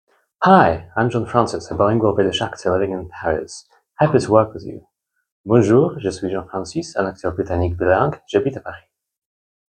Slate – Bilingual British Actor in Paris (En/Fr)
30 - 50 ans - Basse Baryton-basse Baryton